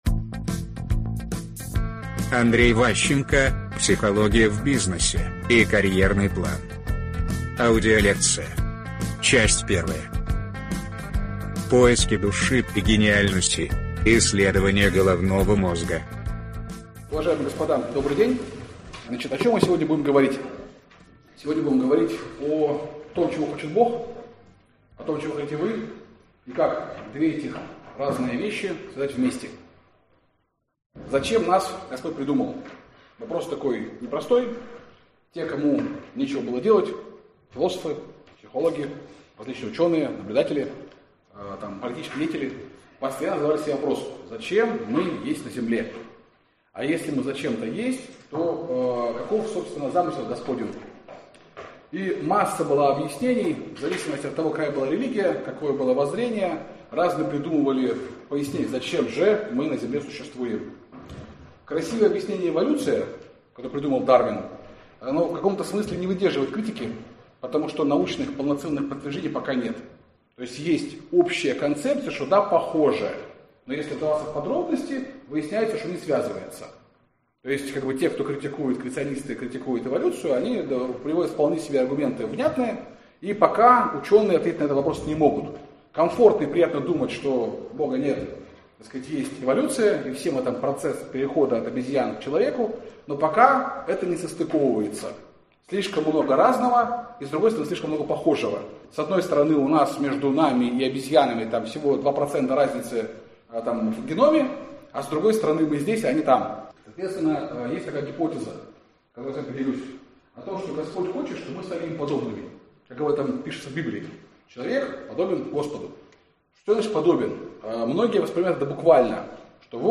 Аудиокнига Психология в бизнесе и карьерный план. Лекция 1 | Библиотека аудиокниг